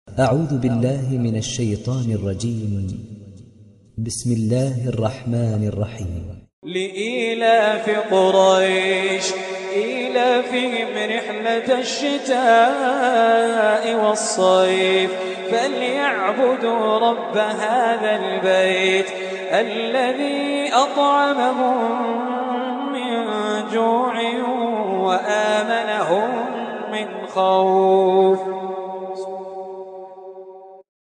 تحميل سورة قريش mp3 بصوت خالد الجليل برواية حفص عن عاصم, تحميل استماع القرآن الكريم على الجوال mp3 كاملا بروابط مباشرة وسريعة